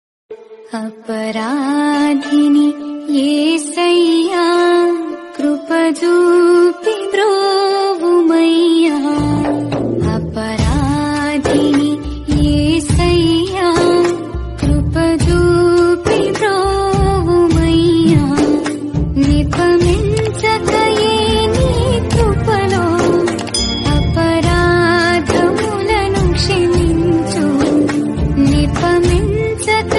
melody ringtone download